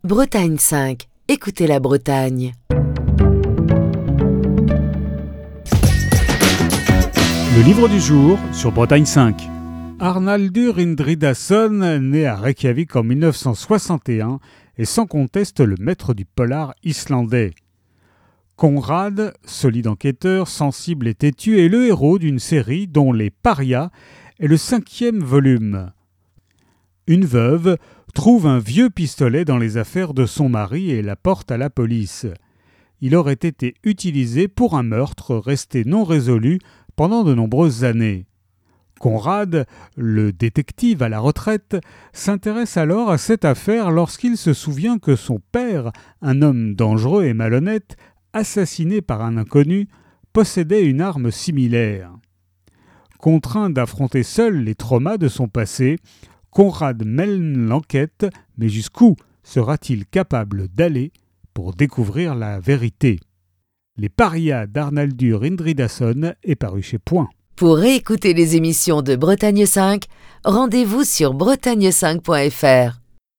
Chronique du 18 juillet 2025.